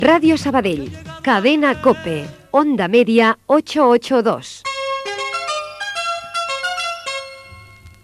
Indicatiu de l'emissora i freqüència